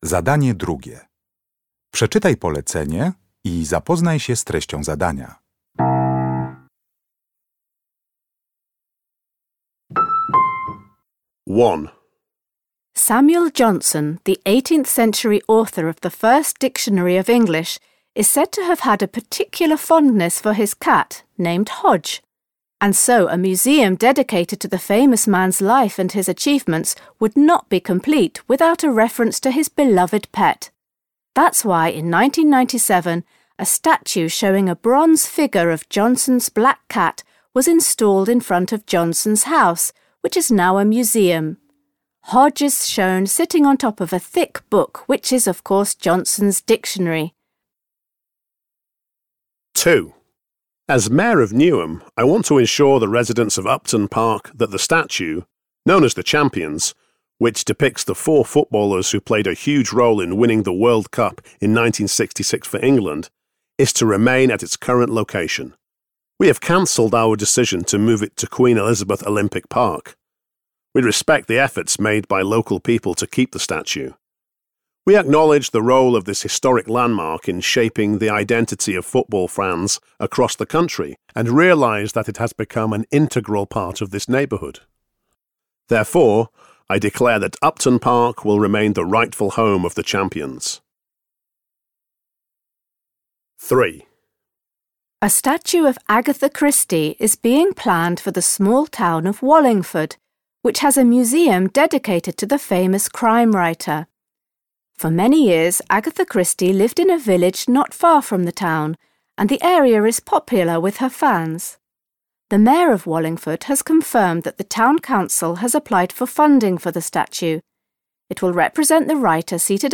Uruchamiając odtwarzacz z oryginalnym nagraniem CKE usłyszysz dwukrotnie pięć wypowiedzi na temat pomników.